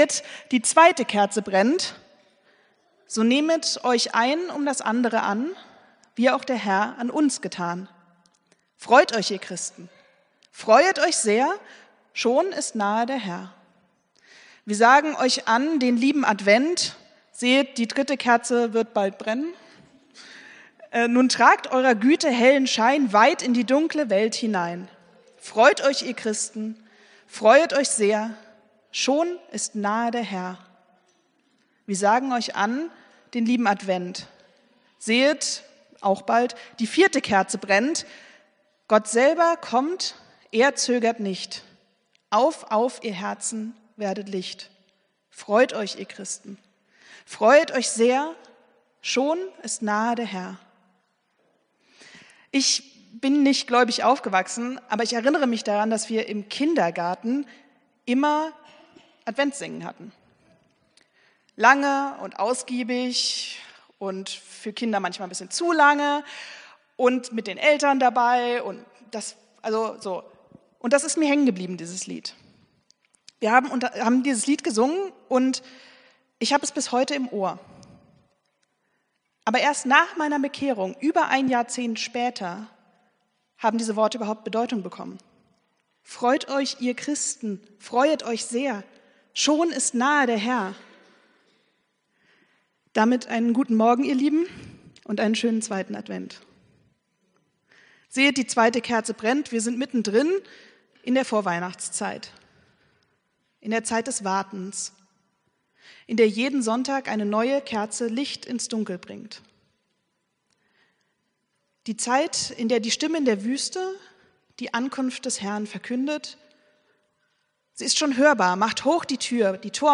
Predigt vom 08.12.2024